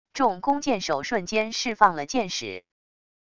众弓箭手瞬间释放了箭矢wav音频